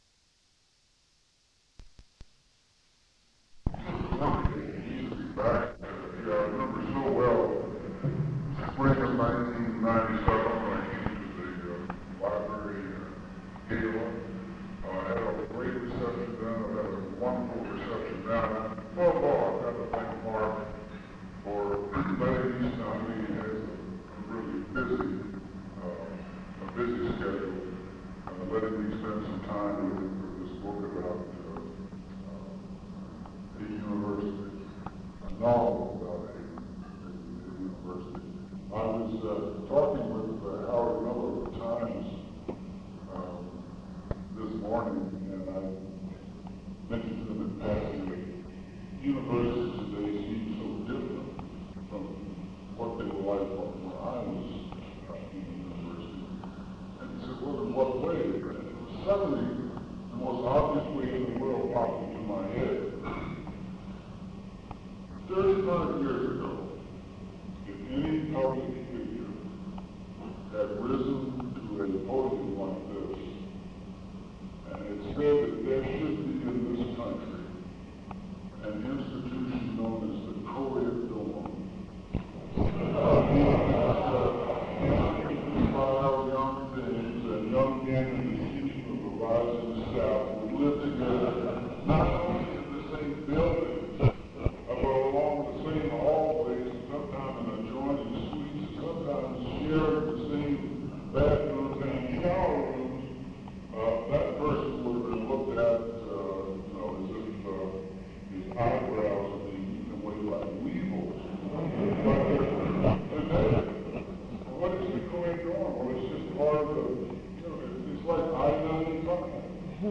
Description Side one of tape is a recording of a speech given by Tom Wolfe to a Rotary Club on the making and the early years on the United States space program.
Oral History